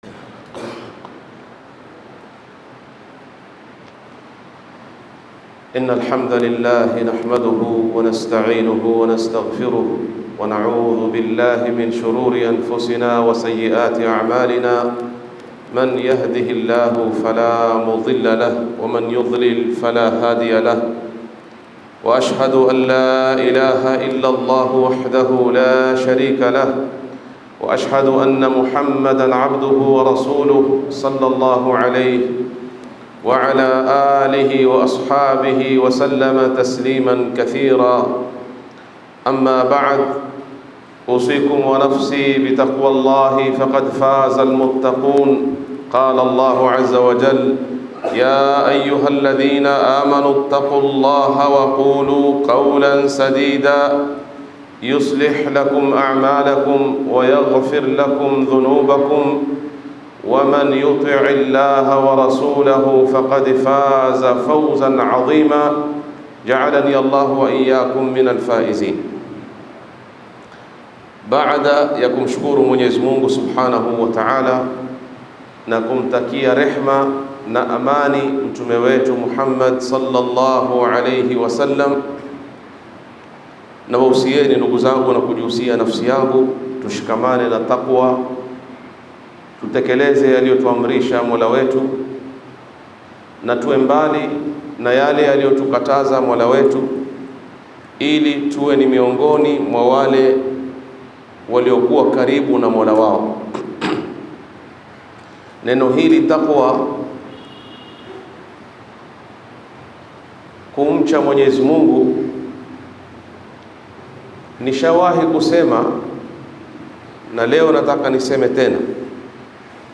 Khutbah